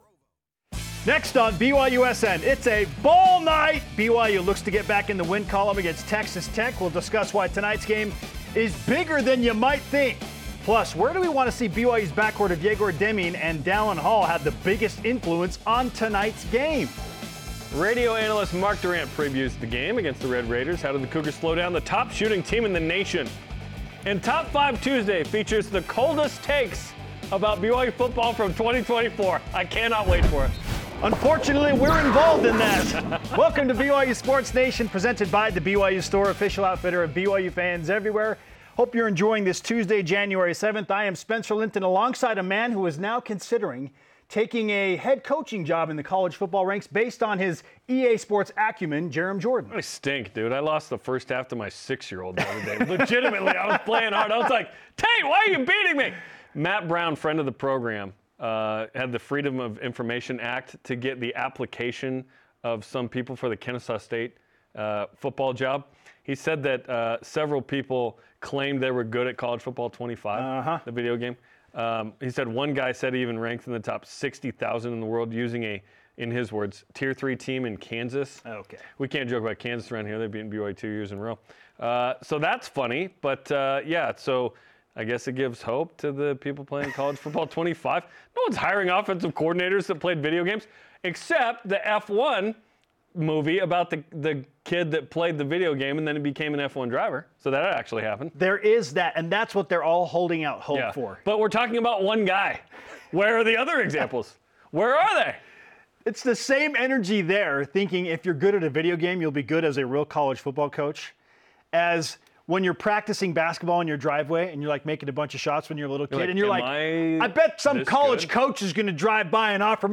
You’ll get in-depth play analysis, athlete interviews, and gripping commentary on all things BYU Football, Basketball, and beyond.